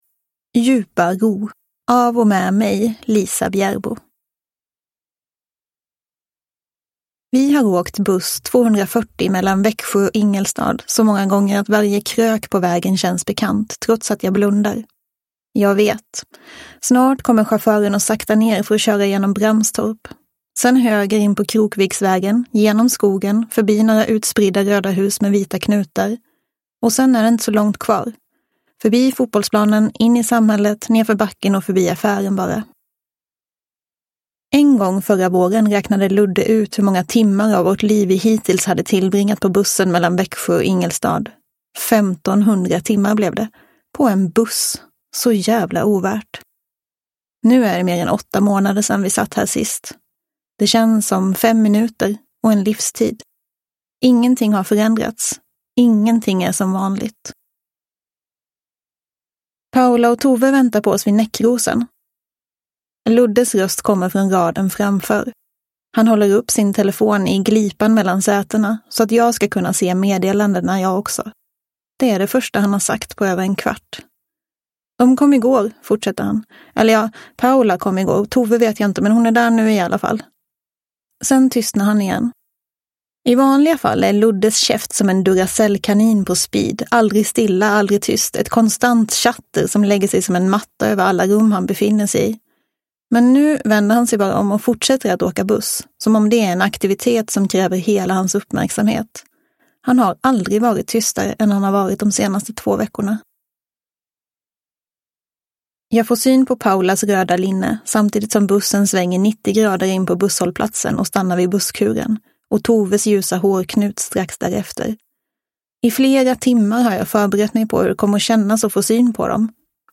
Djupa Ro – Ljudbok – Laddas ner